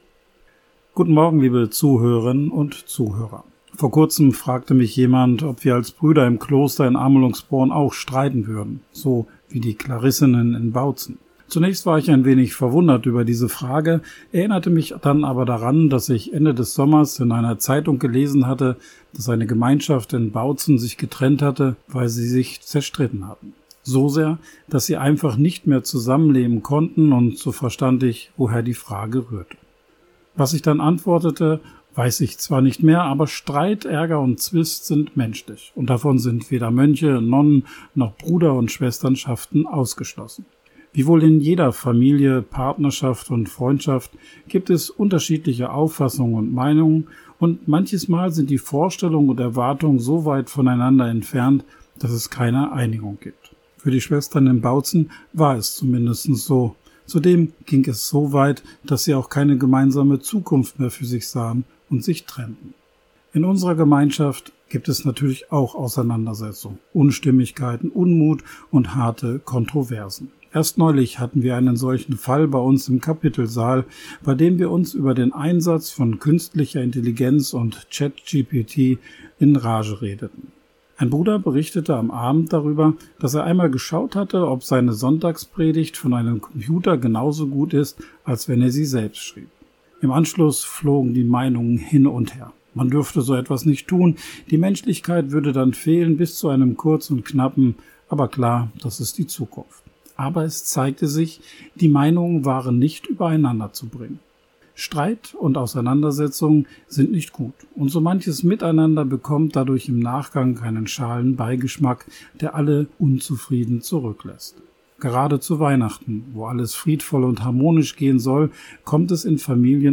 Radioandacht vom 20. Dezember